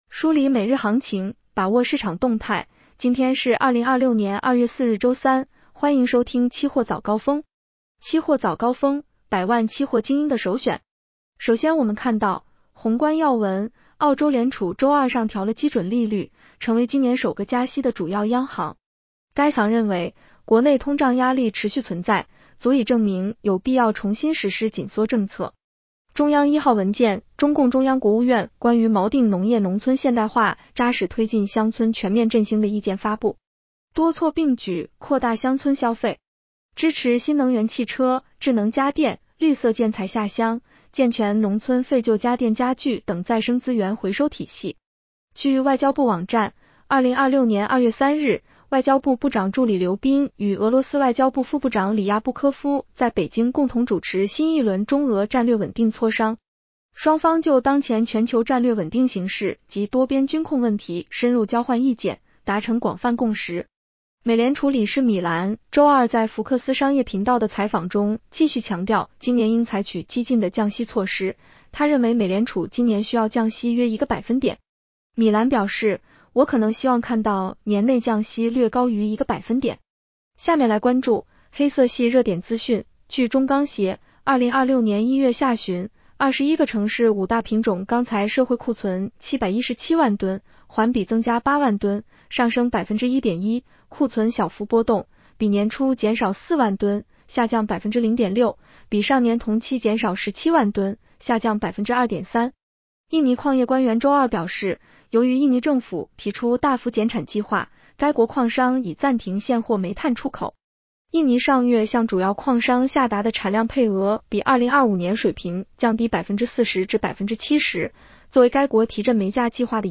期货早高峰-音频版 女声普通话版 下载mp3 热点导读 1.上期所调整白银期货相关合约涨跌停板幅度和交易保证金比例。 2.广期所调整铂、钯期货合约涨跌停板幅度和交易保证金标准。